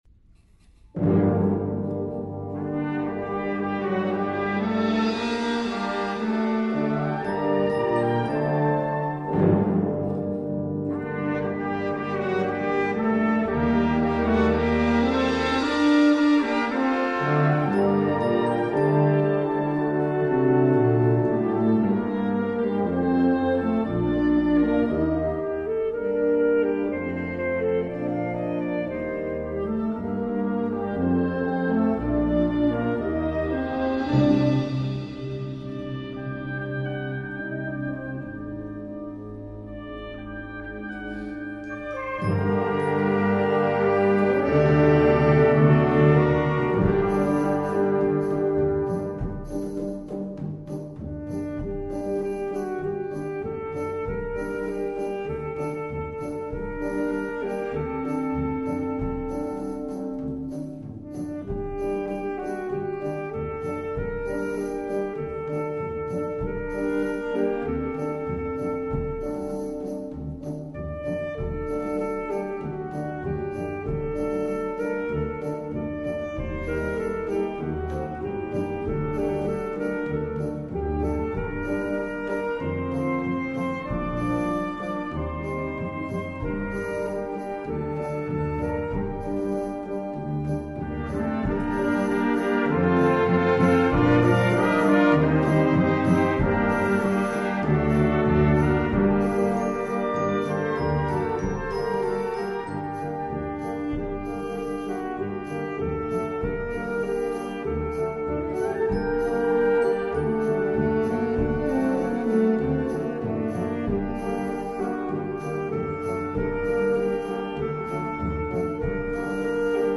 Solo für Altsaxophon und Blasorchester